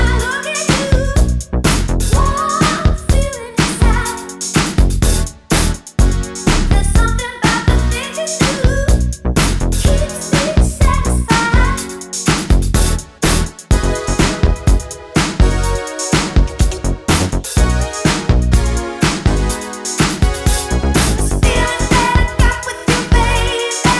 no kit bass or main guitar Soul / Motown 4:09 Buy £1.50